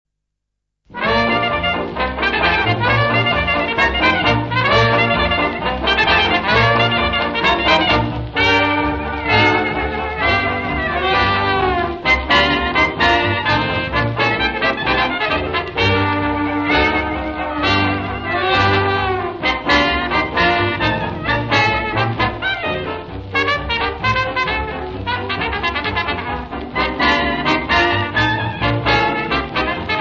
Tutte leregistrazioni sono state effettuate a Chicago
• Registrazione sonora musicale